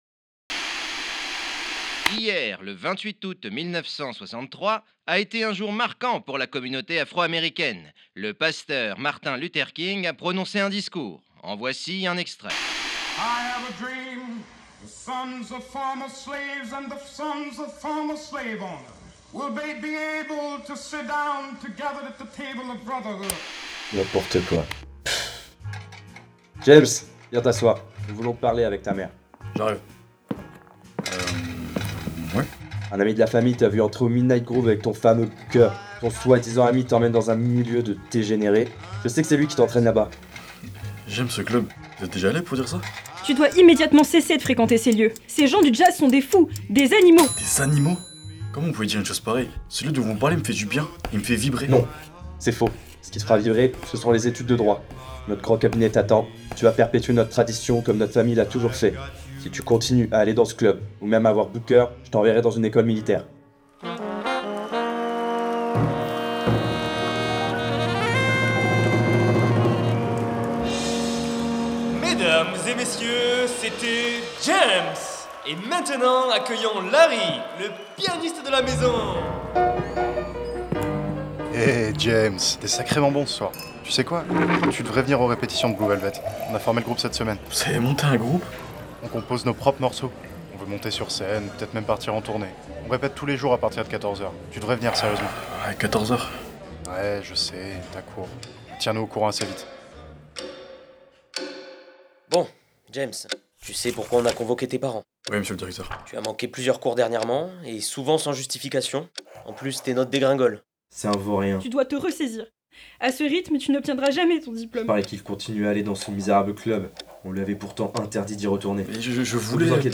Genre : fiction sonore documentaire et musicale